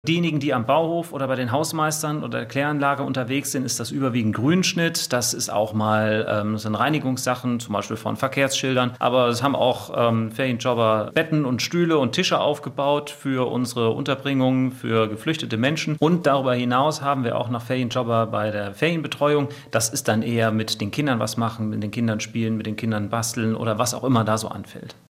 Wir haben Bürgermeister Hannes Gieseler gefragt, wie es so läuft.